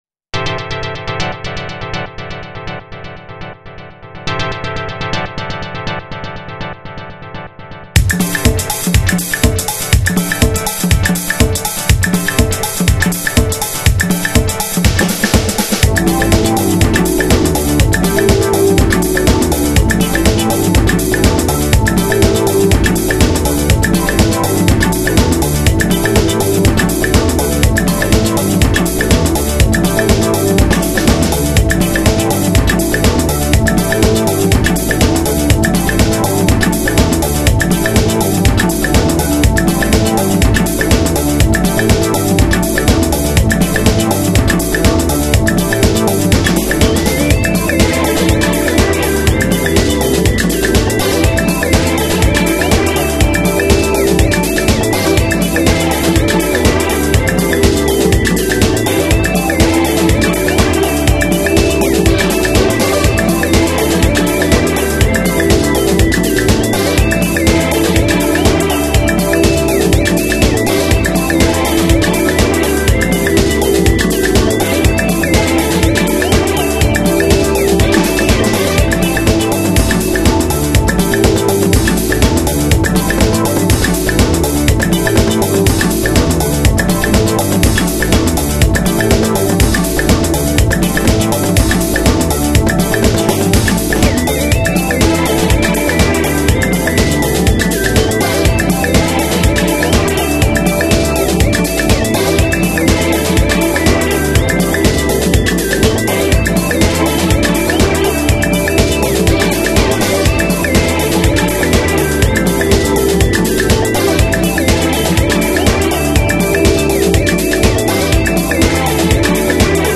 幅広く 『ハウス』 の攻略を試みたオリジナル曲集。